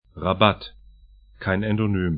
Pronunciation
Rabat ra'bat Ar Ribt ar Stadt / town 33°57'N, 06°50'W